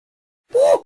Gnome Sound Effect - Bouton d'effet sonore